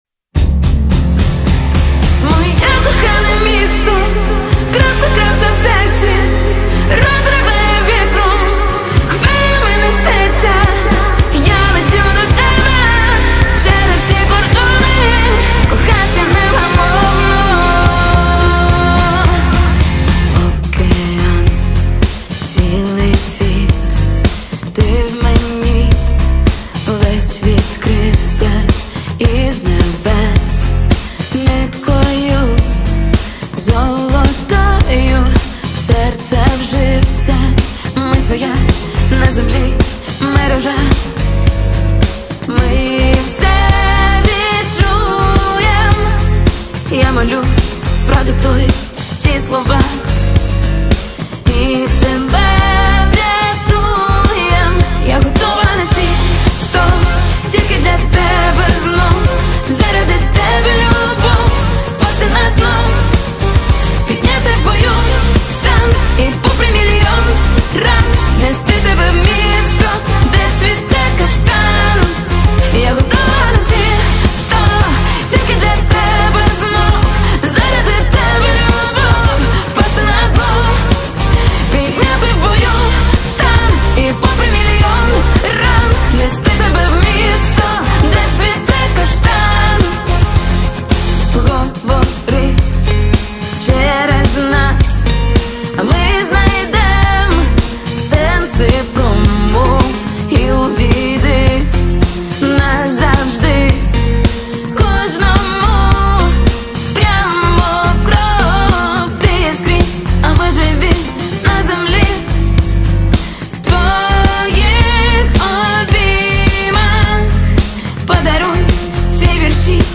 • Жанр: Рок